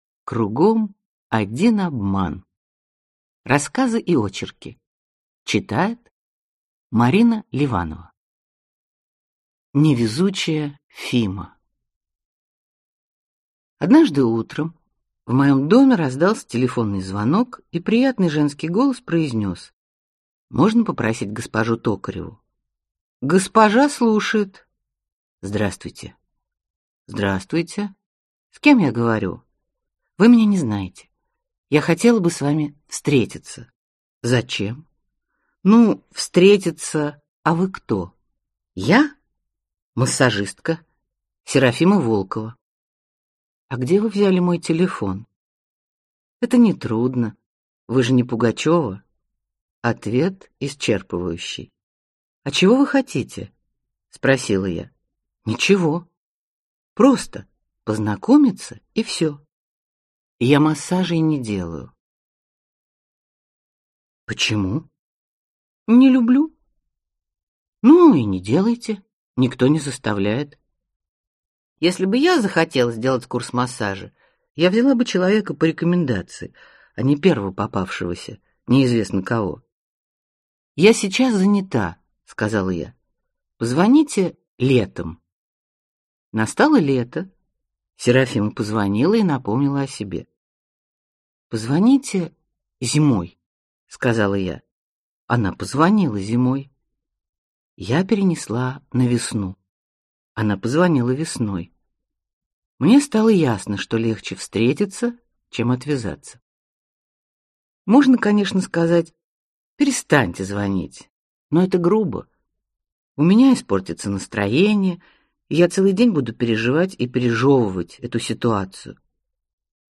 Аудиокнига Кругом один обман (сборник) | Библиотека аудиокниг